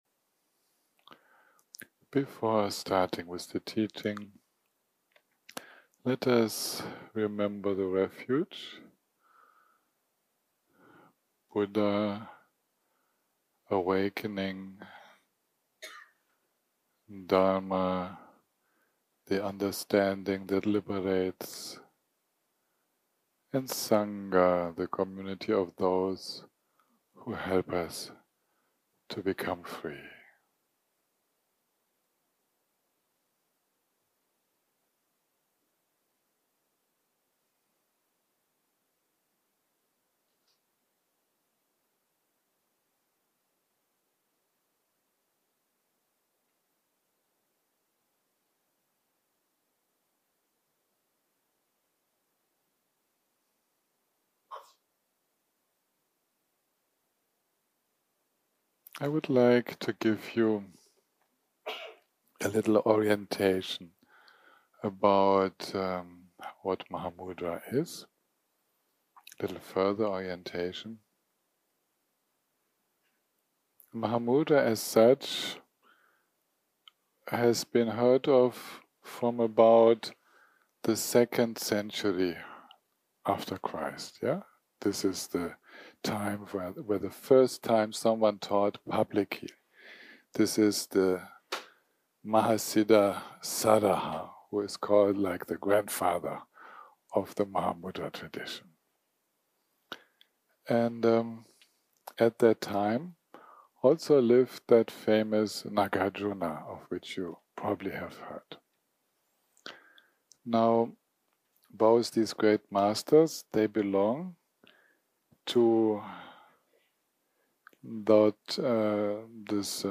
יום 2 - הקלטה 7 - אחהצ - שיחת דהרמה - Further orientation - What Mahamudra is 0:00 0:00 סוג ההקלטה: סוג ההקלטה: שיחות דהרמה שפת ההקלטה: שפת ההקלטה: אנגלית